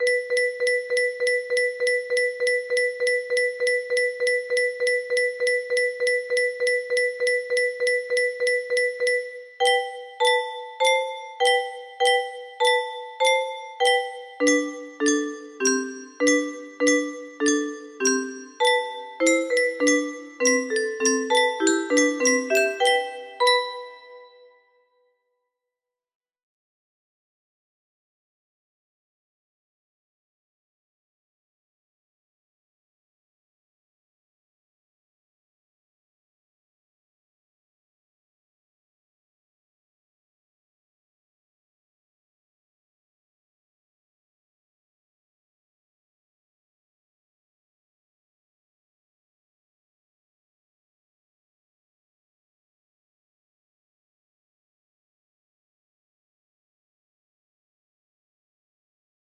Track 1 � music box melody